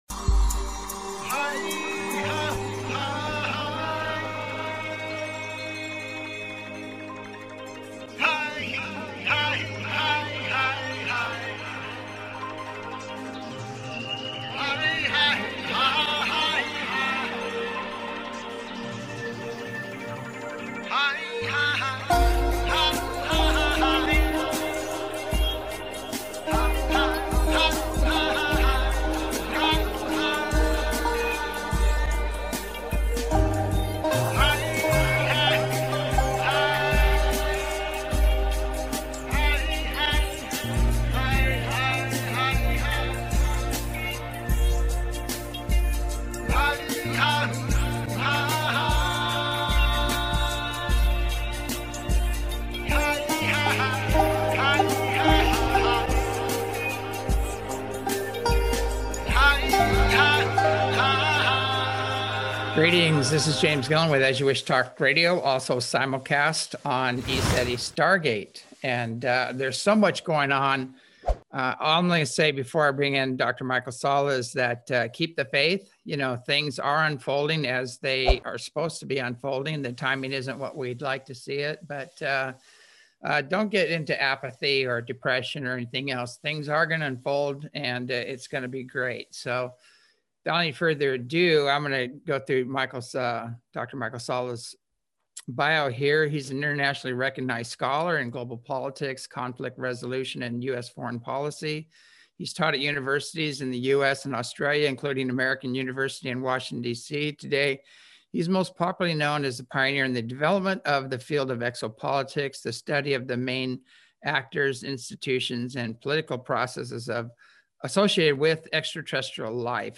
As You Wish Talk Radio, January 23, 2021